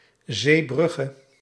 Zeebrugge (Dutch pronunciation: [zeːˈbrʏɣə]
Nl-Zeebrugge.ogg.mp3